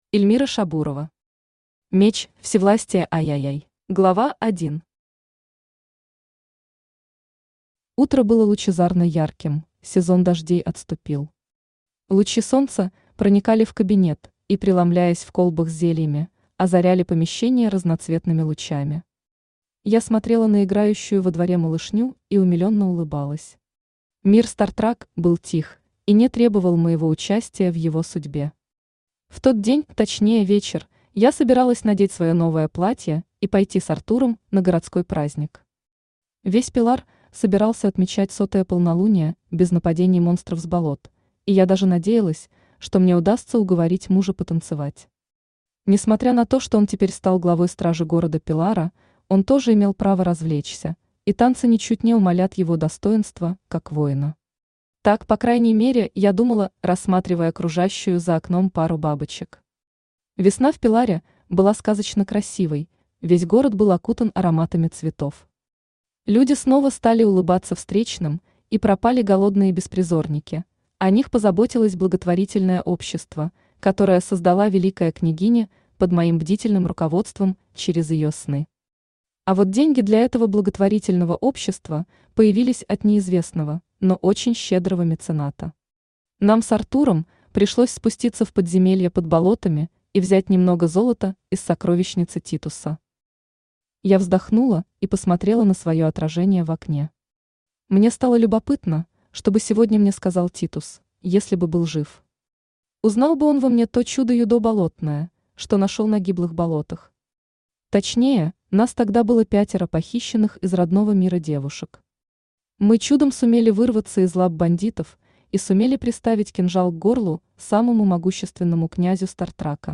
Aудиокнига Меч всевластия III Автор Эльмира Шабурова Читает аудиокнигу Авточтец ЛитРес.